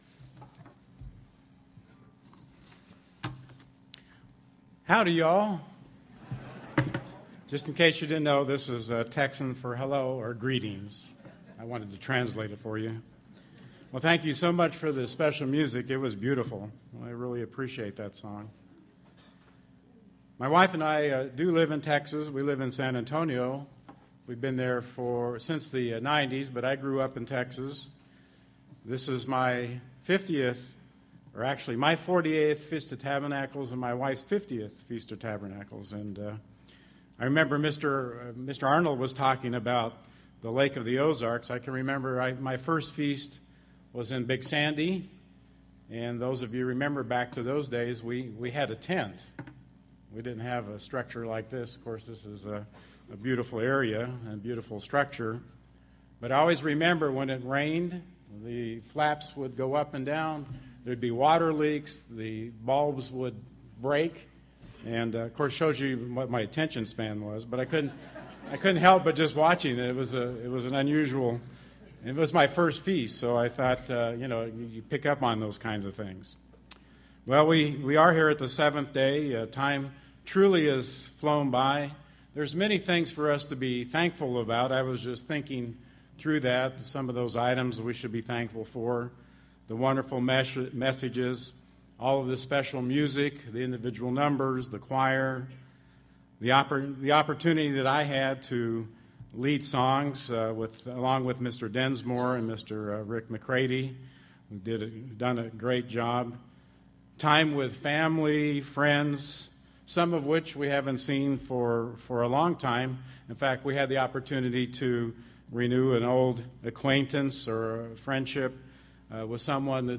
This sermon was given at the Pigeon Forge, Tennessee 2014 Feast site.